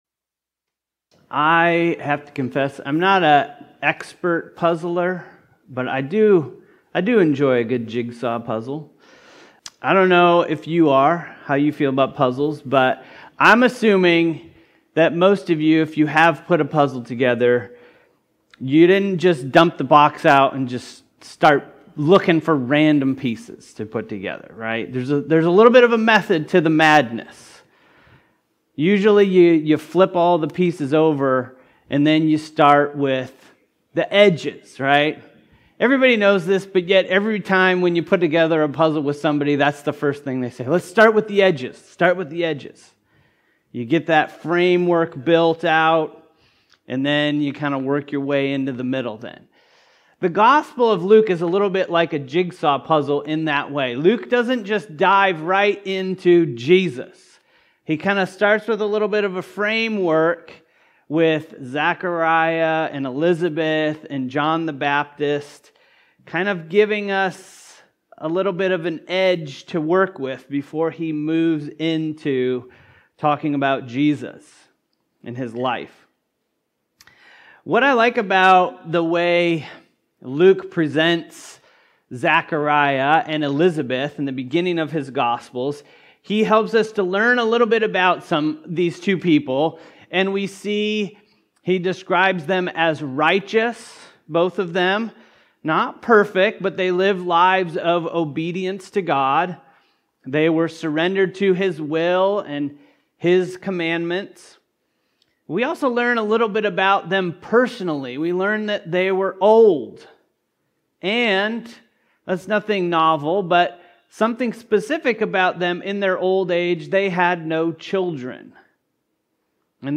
Zechariah's Song: The Light of Salvation and Knowledge | Luke 1 Sermon